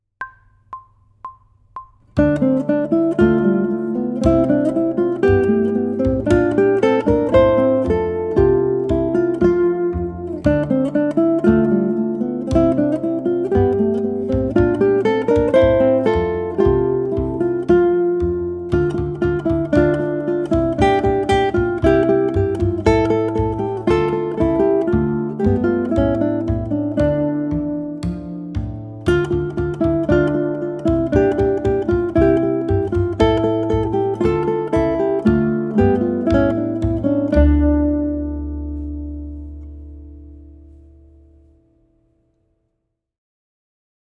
For our final ensemble offering, we partake of a trio from the Elizabethan era, “Sweet Kate” by Robert Jones.
The witty and playful dialogue between the two lovers is depicted by the echoing back and forth between guitar 1 and guitar 2. Guitar 3, normally a supporting bassline, joins the dialogue for a couple of measures in the second half.
Aim for a mildly peppy tempo of 116 to 120 BPM (allegro).
guitar
Sweet_Kate_GTR_Trio.mp3